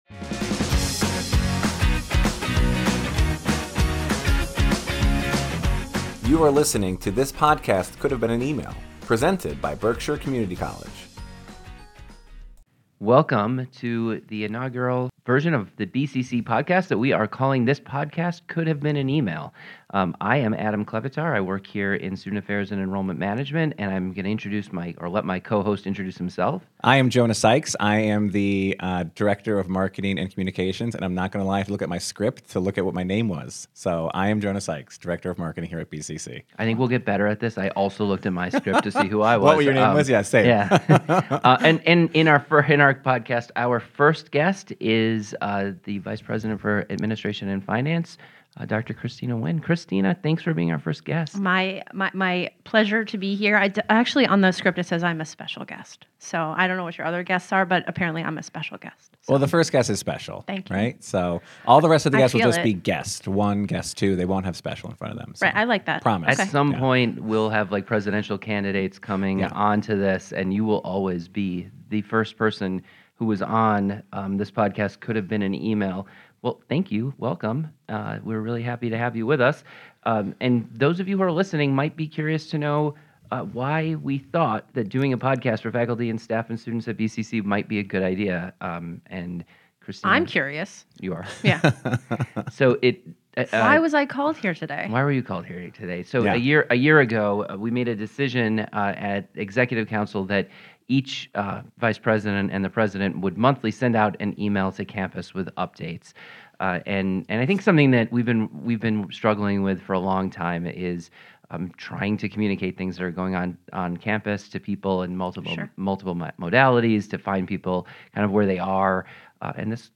Tune in to hear personal stories, updates, plans, forecasts, and informal conversations about life and the goings on in and around BCC.